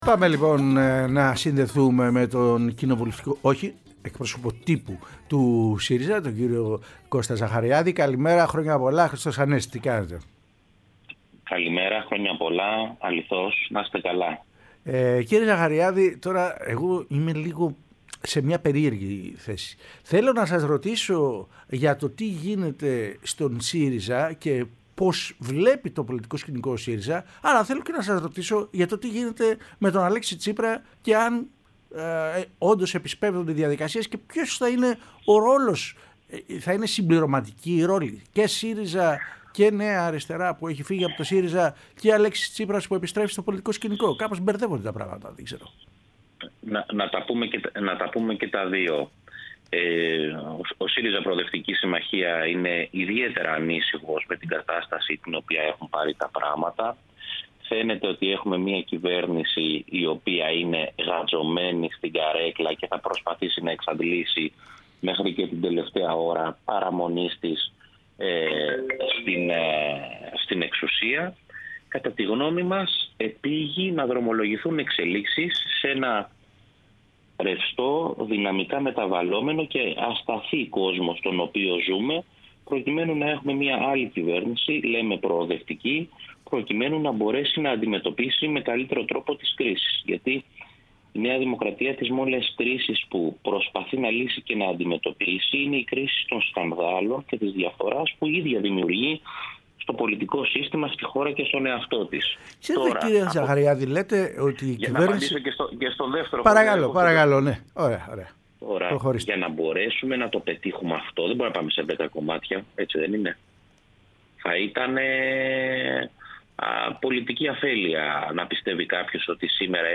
μίλησε στην εκπομπή “Κυριακή μεσημέρι”